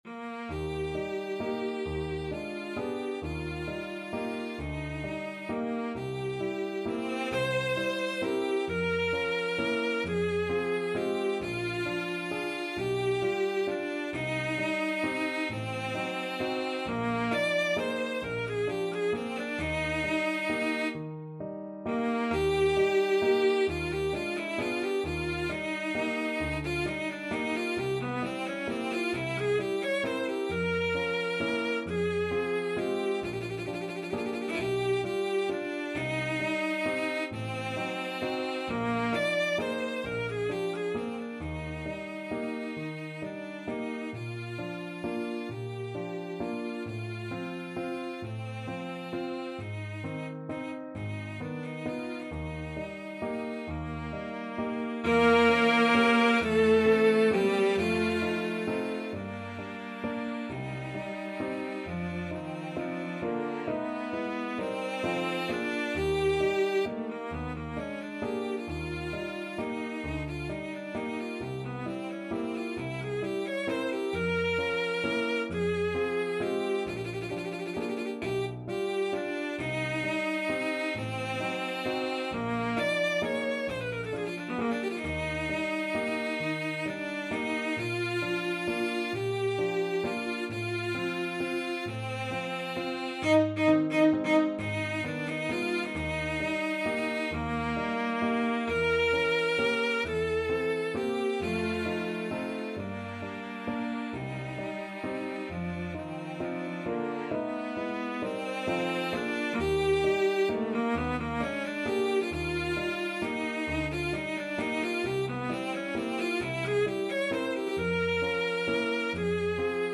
Cello version
CelloPiano
12/8 (View more 12/8 Music)
Andante . = 44
Classical (View more Classical Cello Music)
Nostalgic Music for Cello